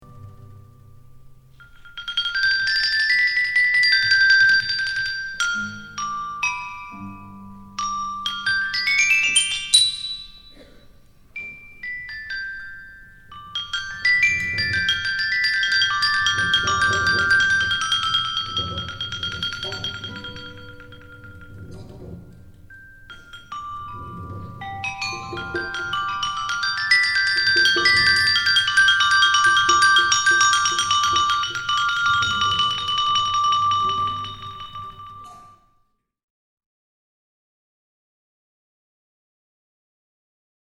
サヌカイト、ギター、打楽器
Sanukite, Guitar, Percussion